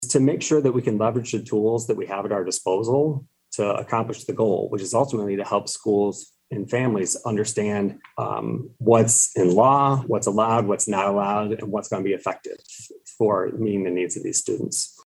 Board of Education meeting.